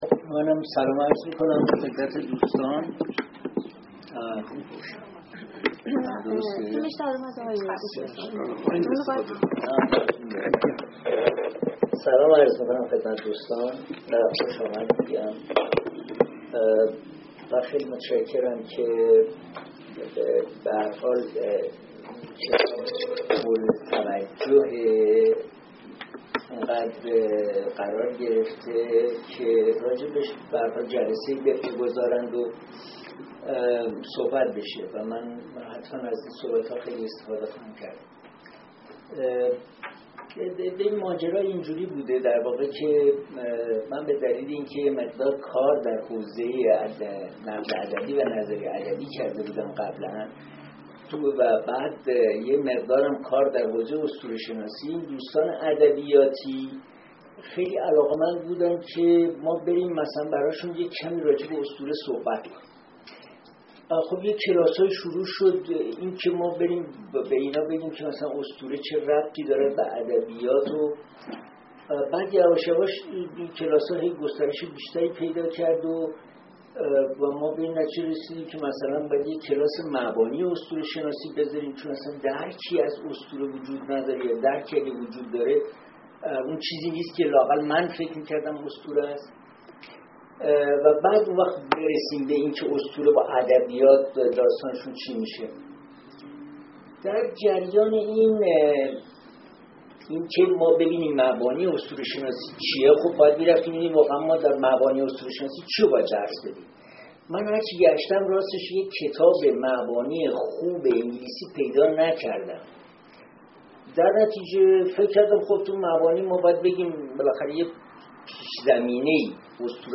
نشست نقد کتاب: «مبانی اسطوره شناسی»
محل برگزاری: سالن اندیشه